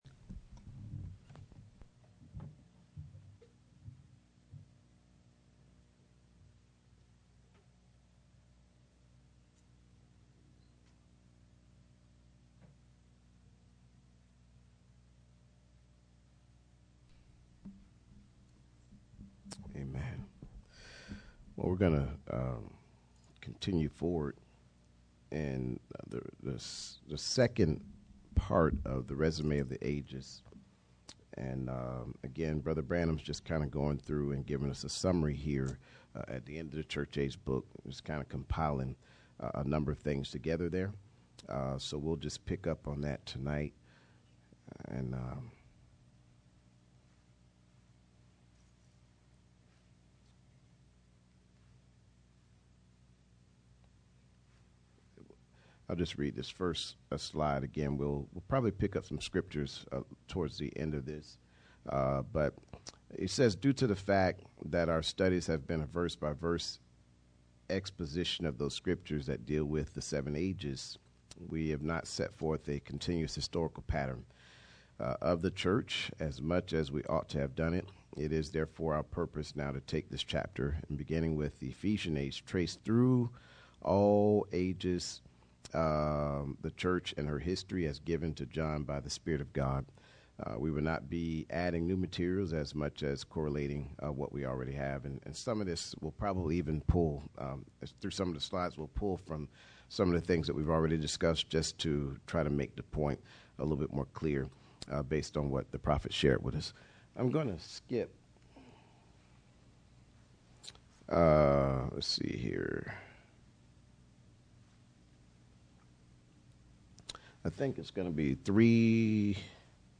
Bible Study Service Type: Midweek Meeting %todo_render% « Pressure Produces Power Church Ages 83